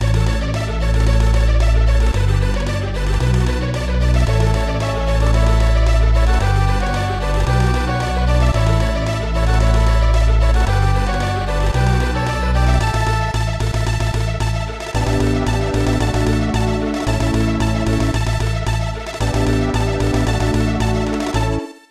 Edited Clipped to 30 seconds and applied fade-out.